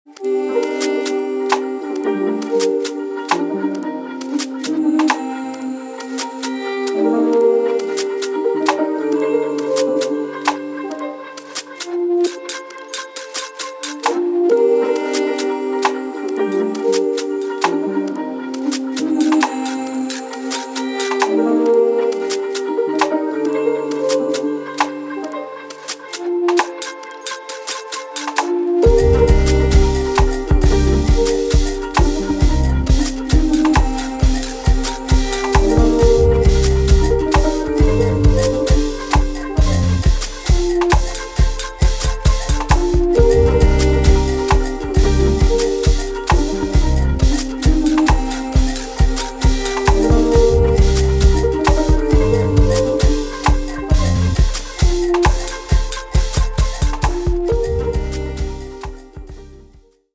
浮遊感や中毒性、温かみを備えたナイスな一枚に仕上がっています。